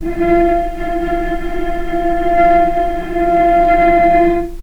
vc-F4-pp.AIF